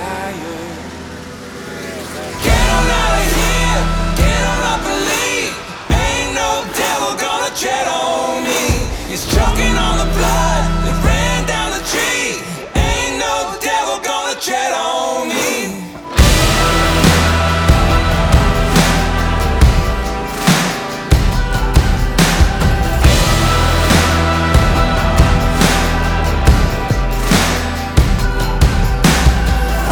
Christian & Gospel